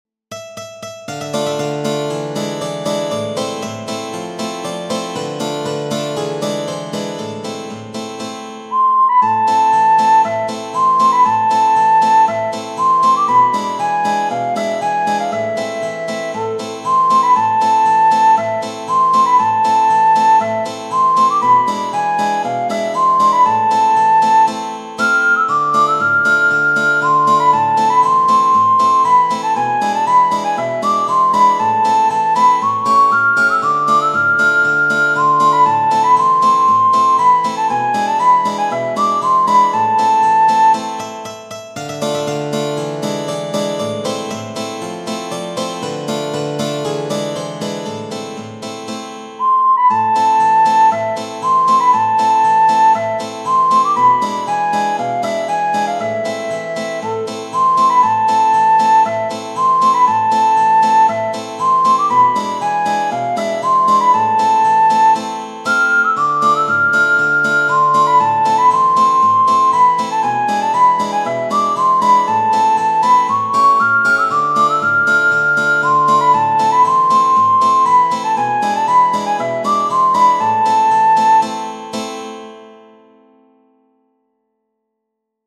Tradizionale Genere: Napoletane Palummella, zompa e vola Addò sta nennella mia!